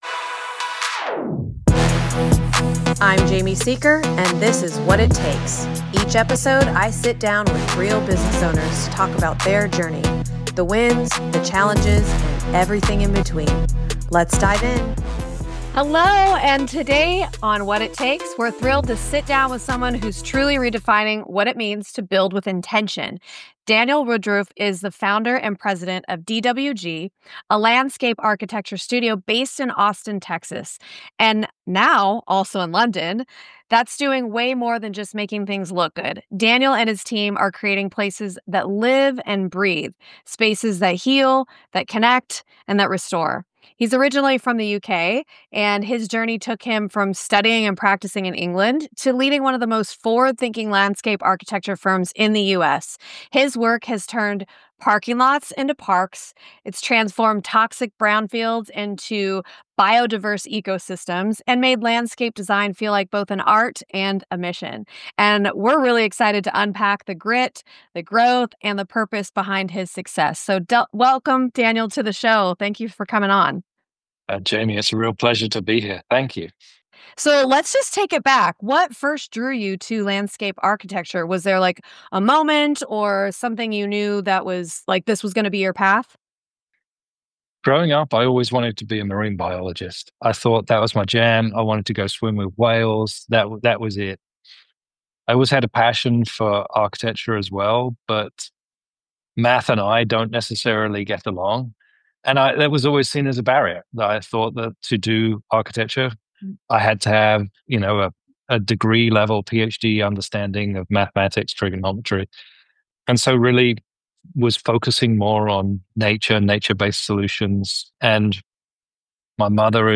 This is a conversation for anyone who’s ever tried to do work that matters, especially when it means pushing back against the status quo.